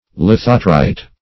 Search Result for " lithotrite" : The Collaborative International Dictionary of English v.0.48: Lithotrite \Lith"o*trite\, Lithotritor \Lith"o*tri"tor\, [See Lithotrity .]